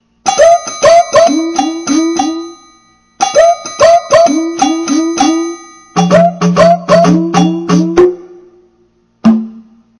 我的Roland套件的一个简短片段。